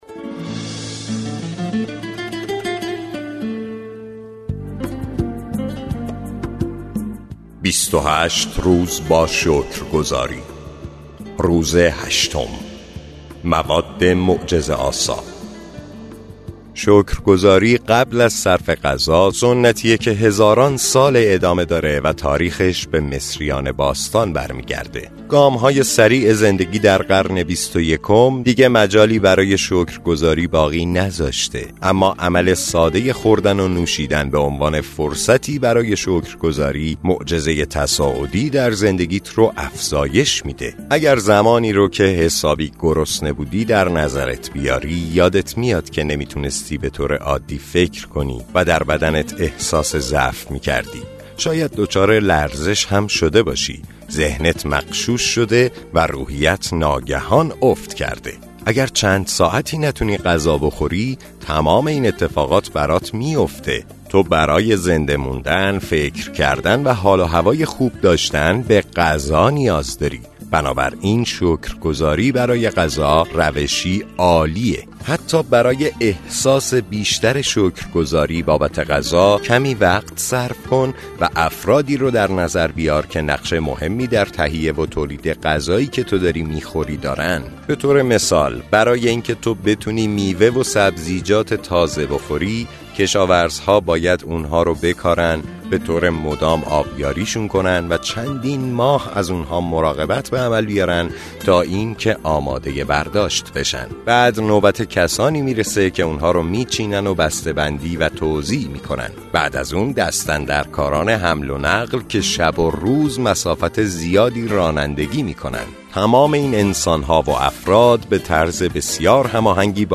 کتاب صوتی معجزه شکرگزاری – روز هشتم